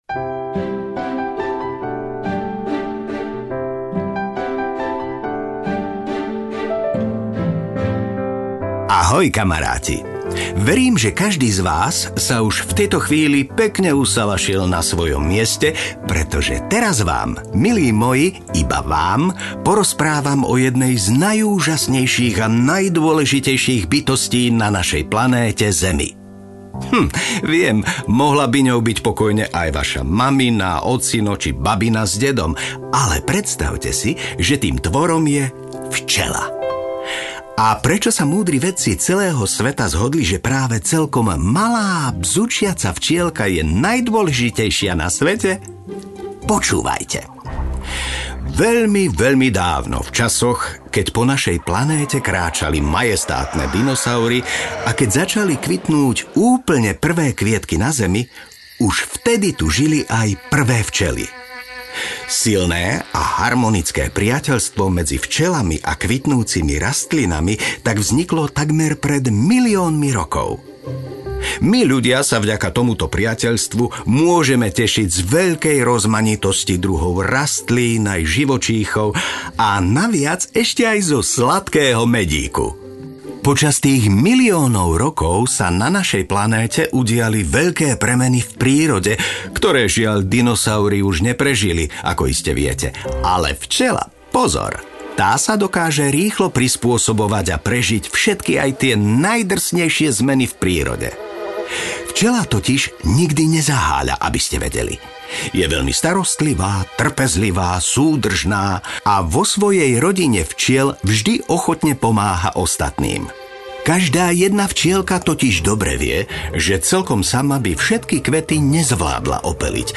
Štúdio SuroSound ozvučilo prednášku a štúdio VOICE CASTING a Sunrise Studio pomohli s jej nahrávkou.